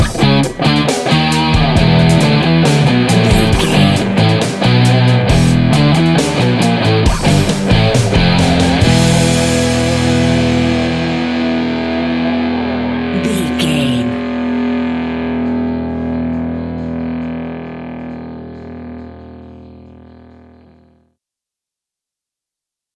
Epic / Action
Aeolian/Minor
hard rock
heavy metal
dirty rock
Heavy Metal Guitars
Metal Drums
Heavy Bass Guitars